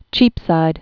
(chēpsīd)